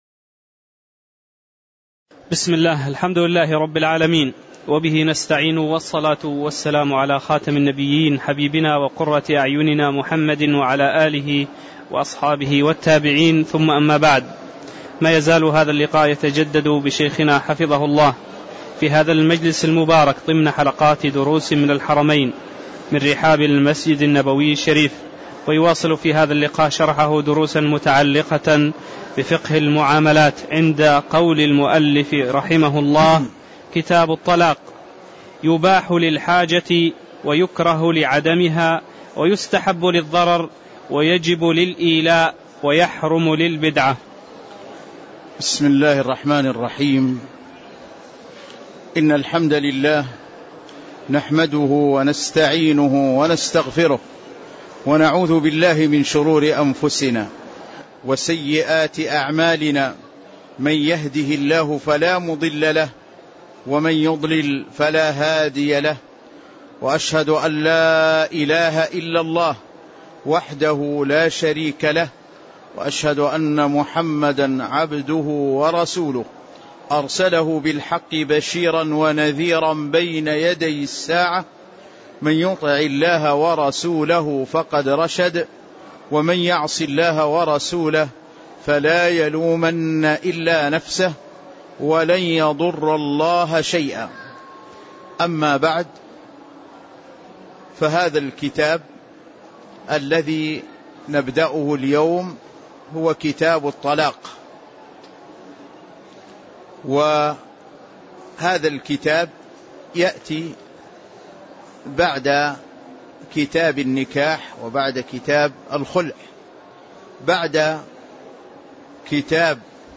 تاريخ النشر ١٨ جمادى الآخرة ١٤٣٧ هـ المكان: المسجد النبوي الشيخ